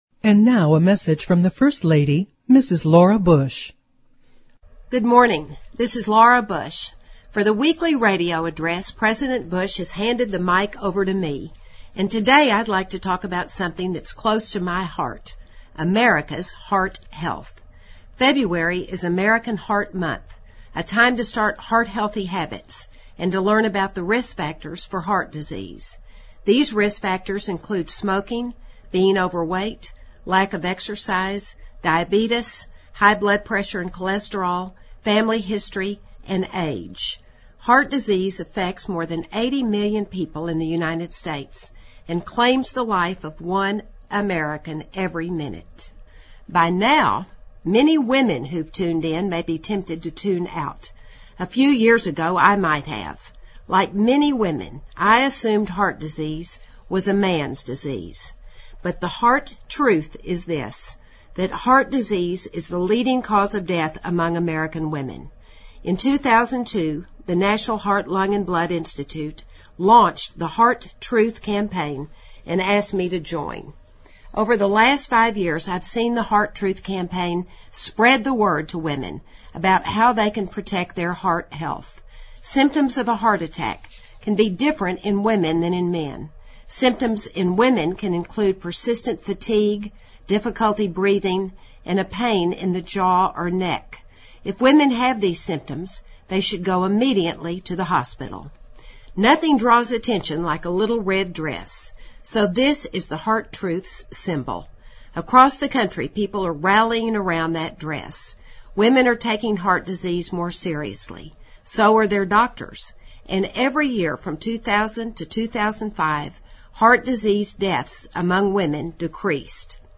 【美国总统夫人电台演说】2008-02-01 听力文件下载—在线英语听力室